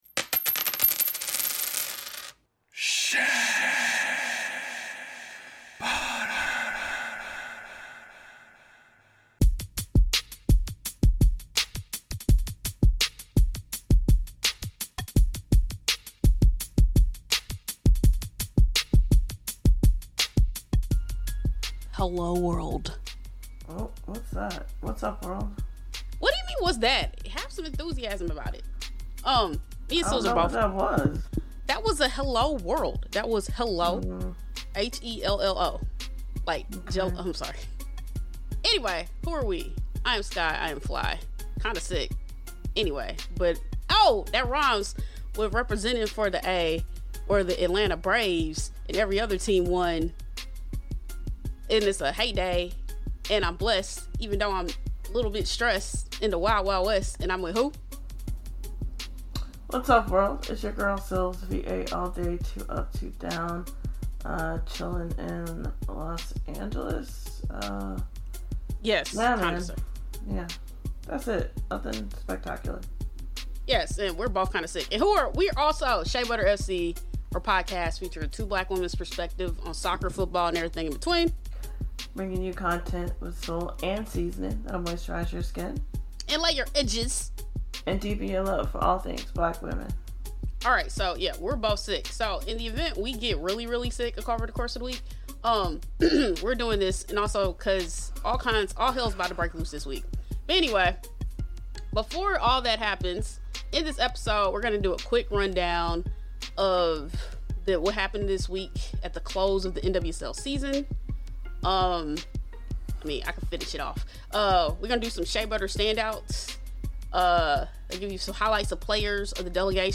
Note: As we were pretty sickly whilst recording, we forgot to shout out to Simone Charley for hitting her 50th cap in the NWSL!!